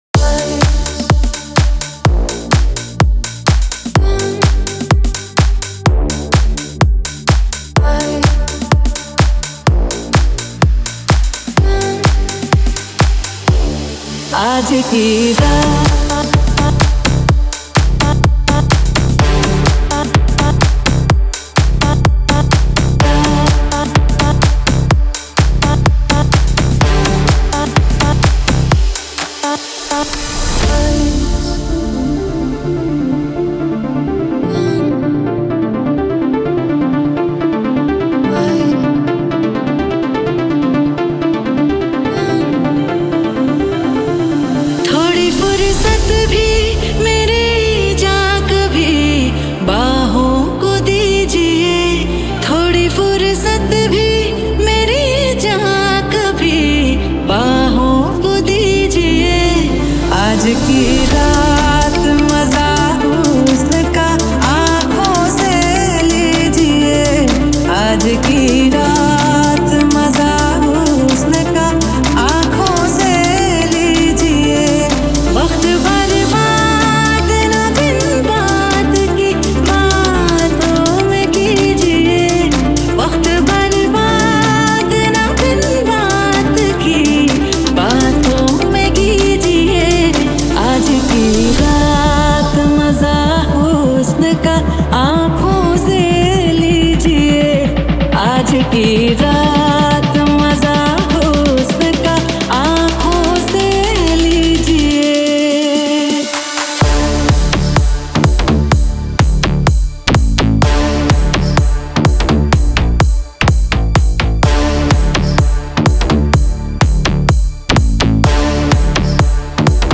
2024 Bollywood Single Remixes Song Name